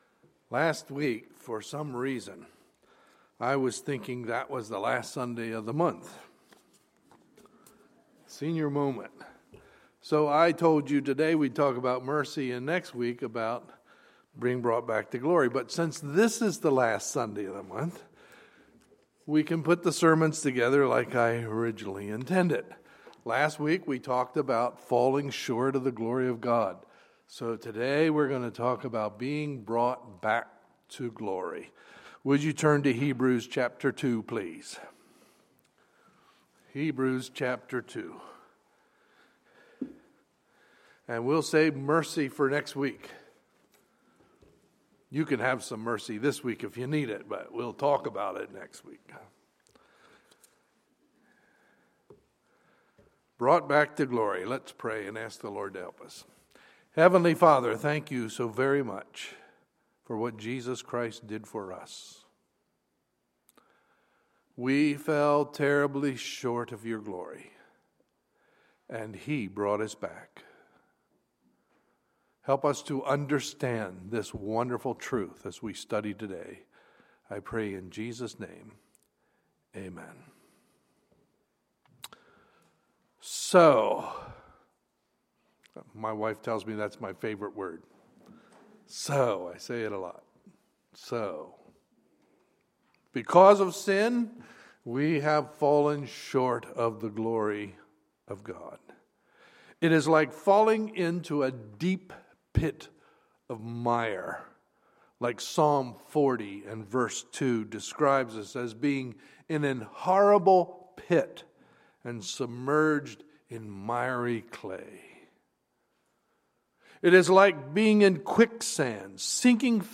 Sunday, February 28, 2016 – Sunday Morning Service
Sermons